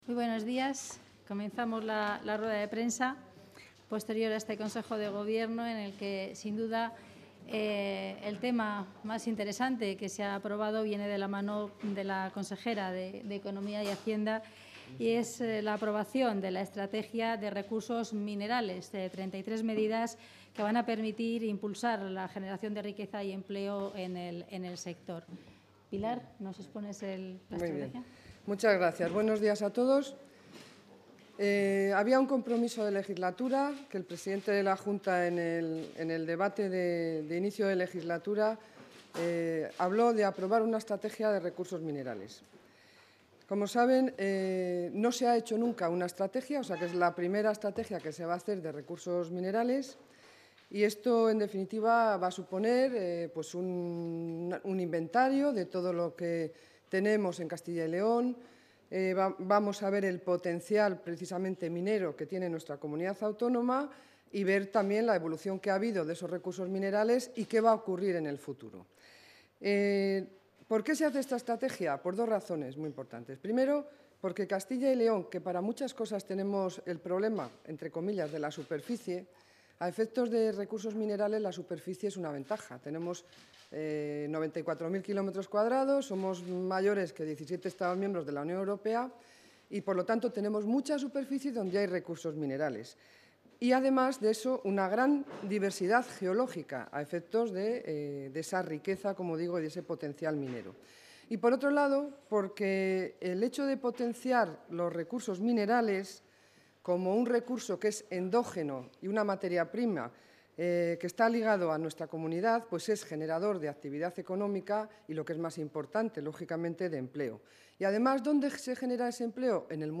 Audio rueda de prensa tras el Consejo de Gobierno.